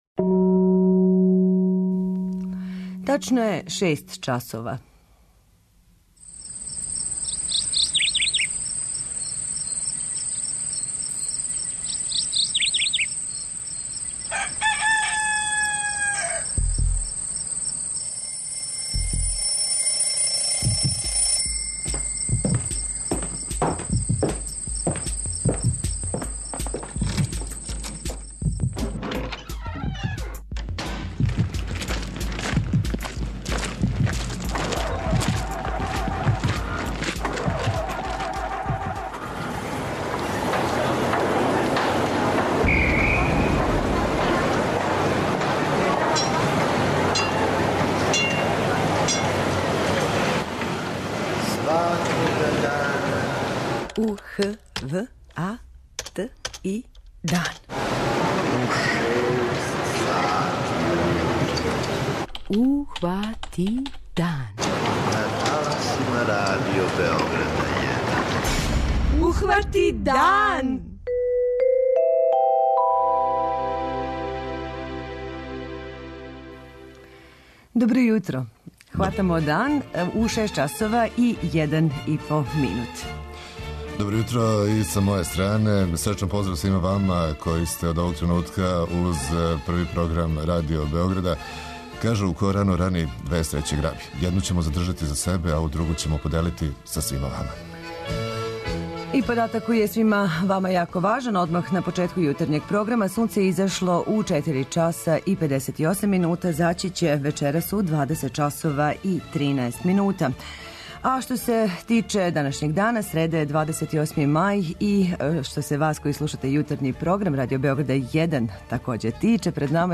Радио Београд 1, радним данима од 06 - 09